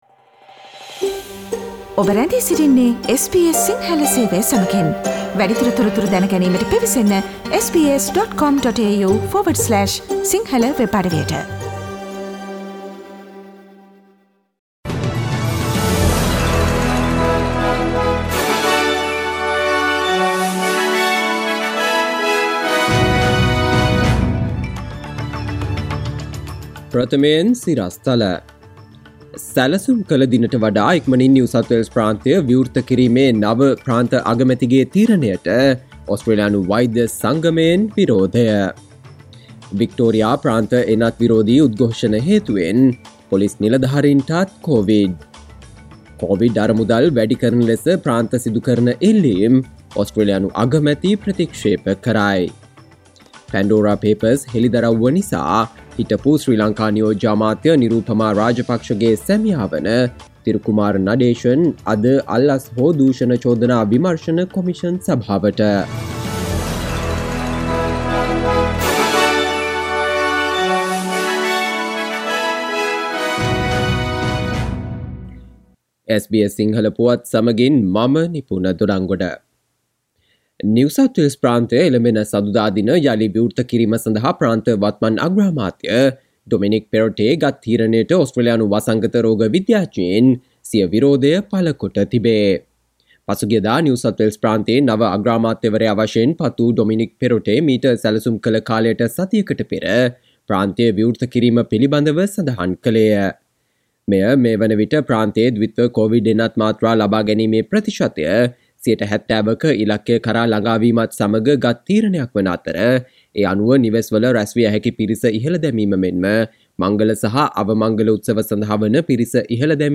සවන්දෙන්න 2021 ඔක්තෝබර් 8 වන සිකුරාදා SBS සිංහල ගුවන්විදුලියේ ප්‍රවෘත්ති ප්‍රකාශයට...